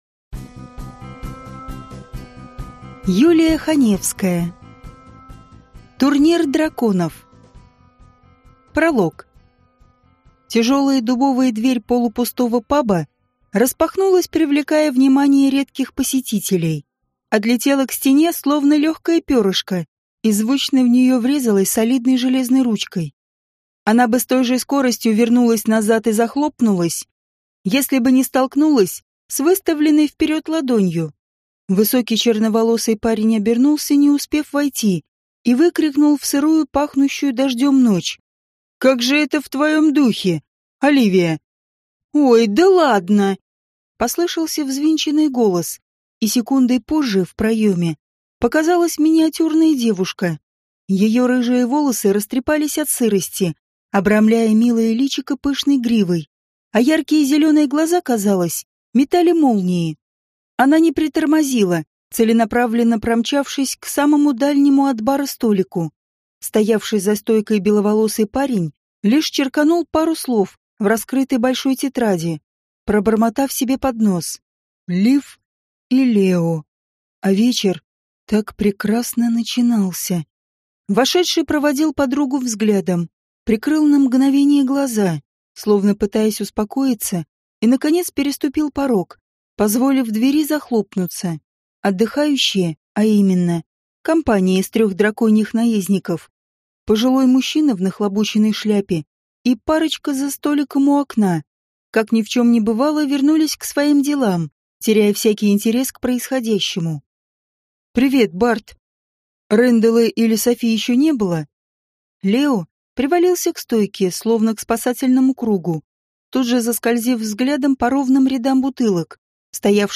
Аудиокнига Турнир драконов | Библиотека аудиокниг